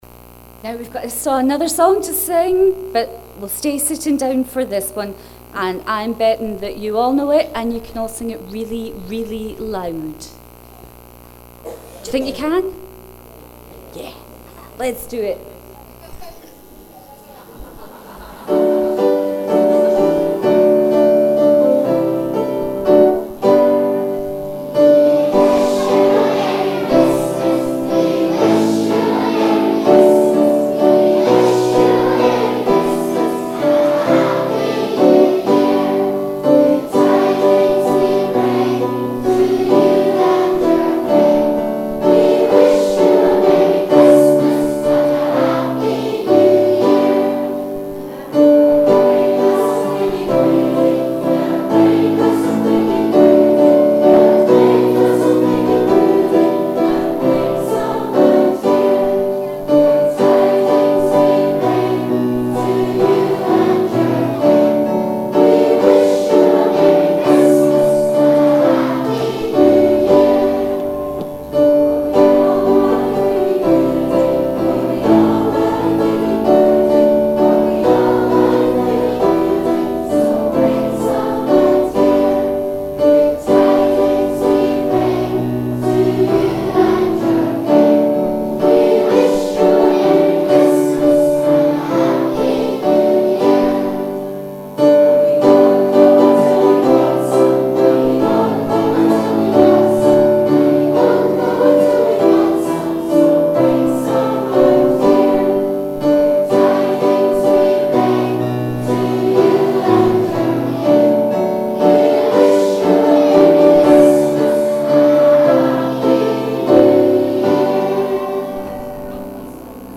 Click on the links to hear the carol singing and service
On Wednesday 5th of December St. Mungo's played host to Penicuik and Midlothian Girlguiding, Rangers, Brownies, Trefoil and Rainbows girls, parents and friends, for their annual Christmas Service.
We wish you a Merry Christmas'  Girlguiding style.